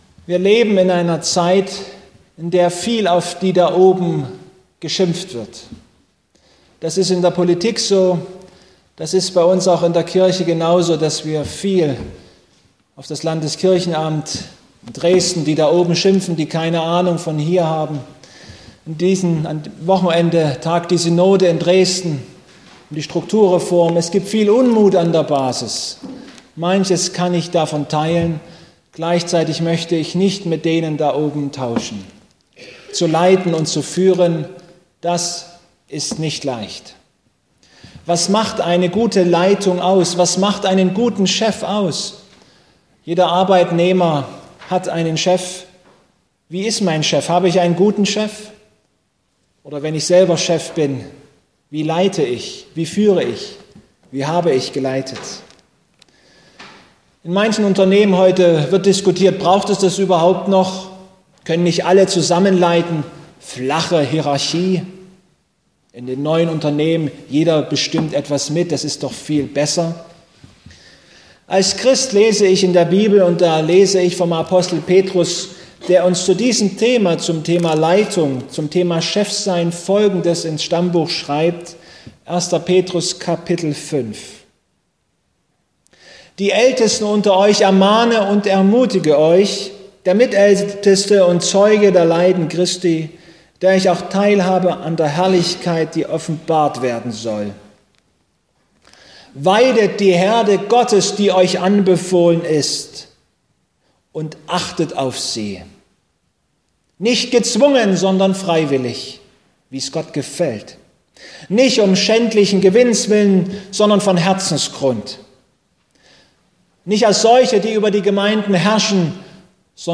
Petr 5,1-4 Gottesdienstart: Abendmahlsgottesdienst Für die Christen in den ersten Jahrhunderten war nicht das Kreuz das wichtigste Symbol, sondern der Hirte.